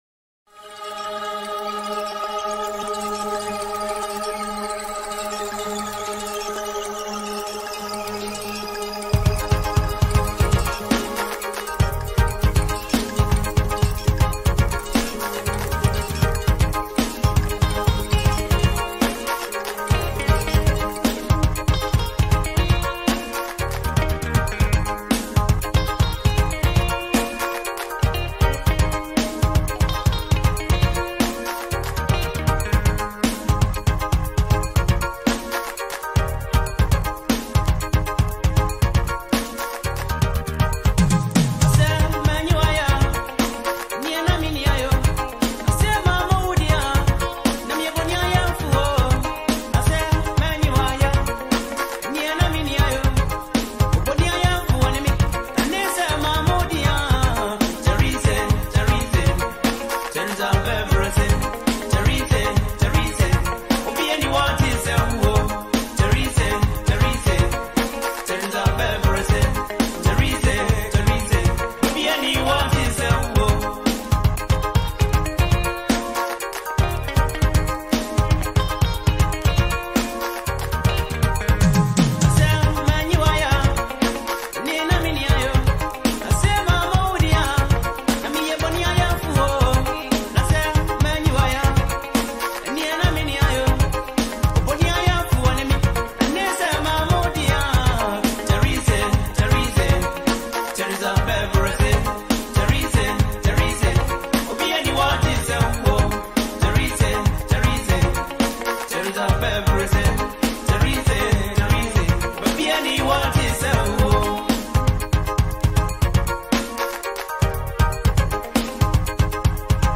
old highlife song